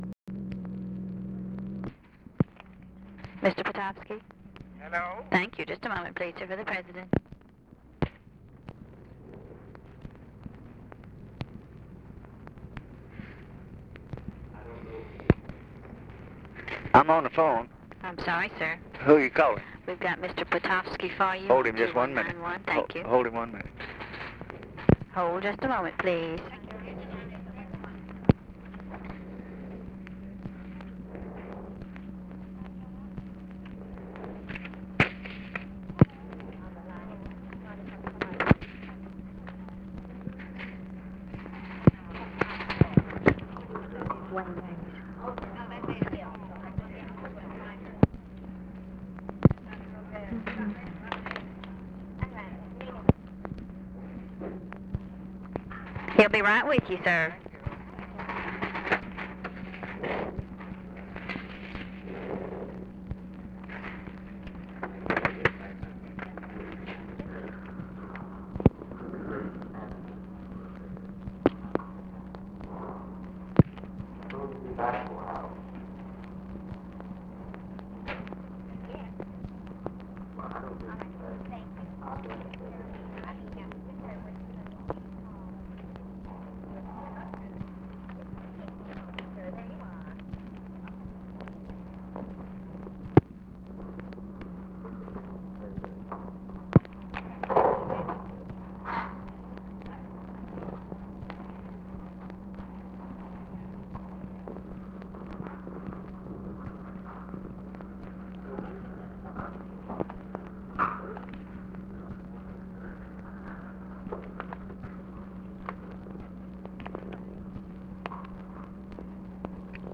Conversation with JAKE POTOFSKY, December 05, 1963
Secret White House Tapes